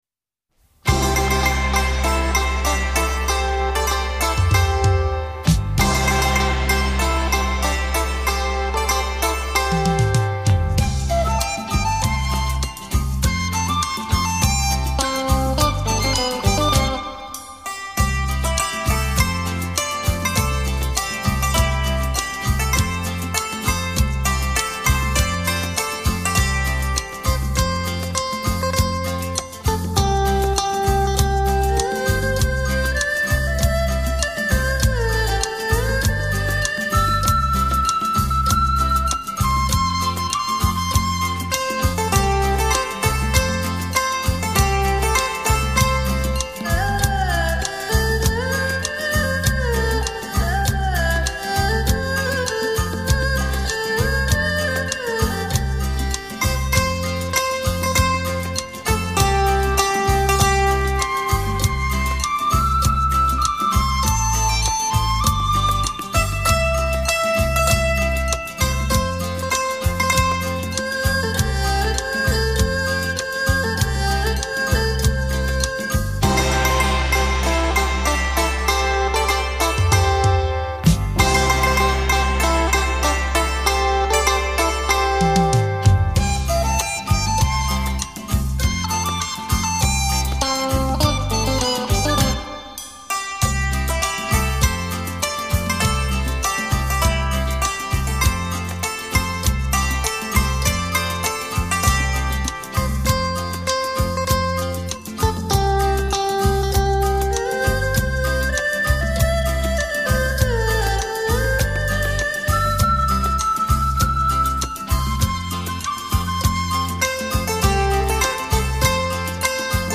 古箏金曲滿天星 特殊演奏傳情意
繞場立體音效 發燒音樂重炫